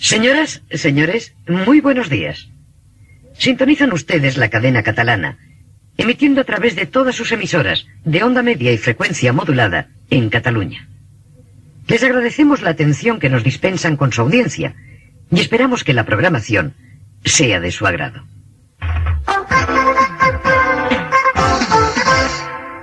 Inici d'emissions, amb identificació i indicatiu musical.